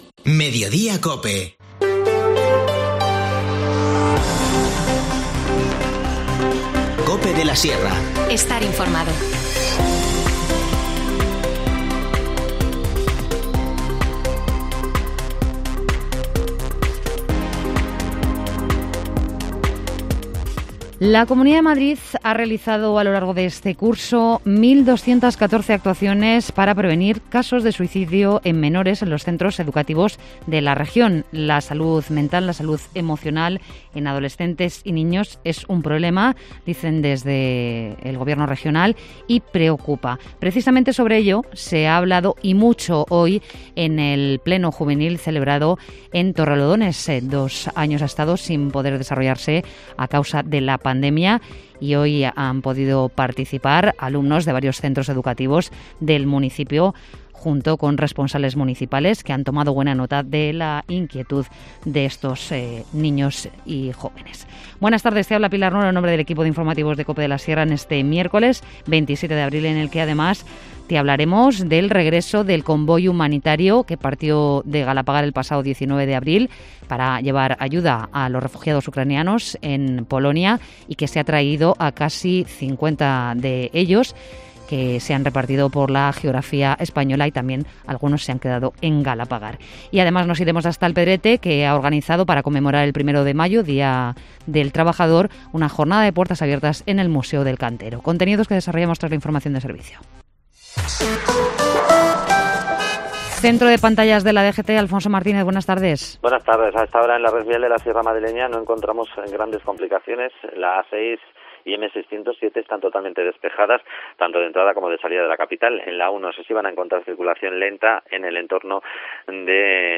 Informativo Mediodía 27 abril
INFORMACIÓN LOCAL